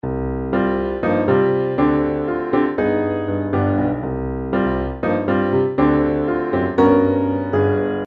有一点拉丁风格，有一点时髦
Tag: 120 bpm Jazz Loops Piano Loops 1.35 MB wav Key : Unknown